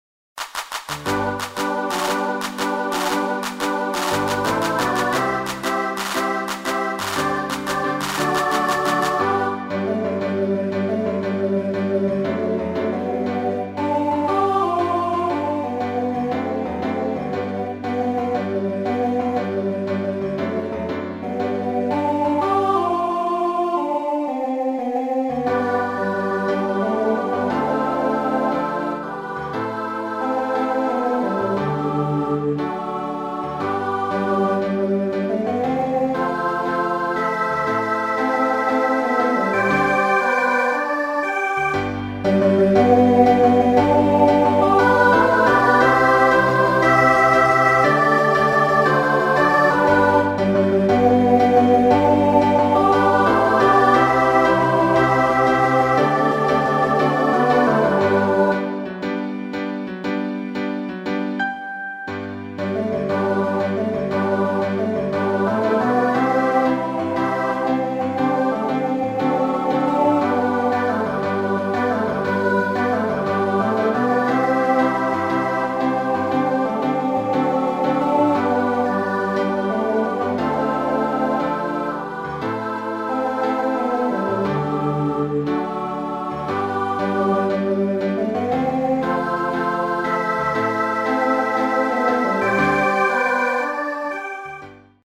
pop song
SATB choir with piano accompaniment